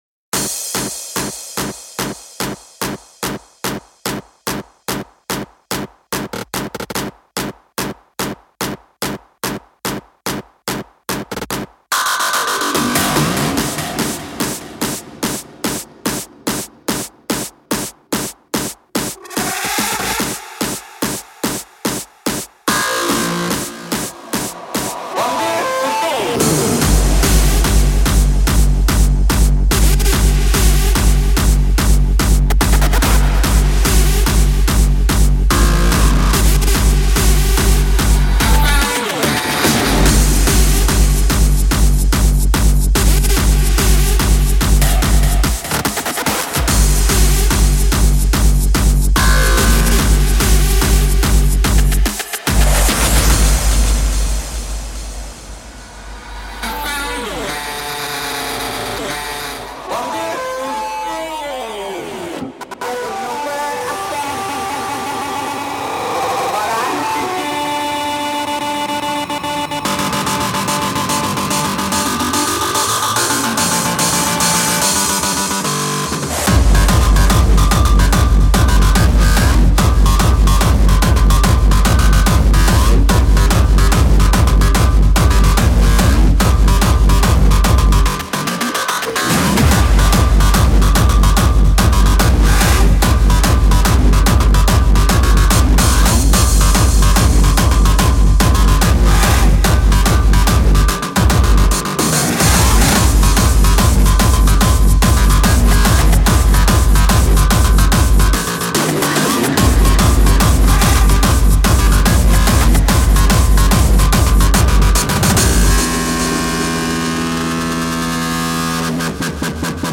hardstyle 4 ever ;)